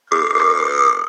Burp Scratch Garden Sound Button - Free Download & Play